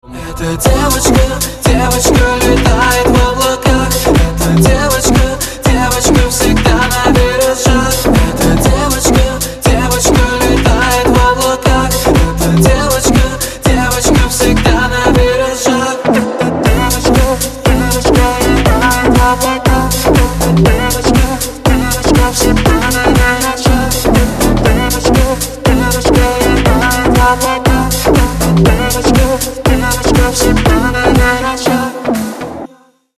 • Качество: 128, Stereo
лирика
dance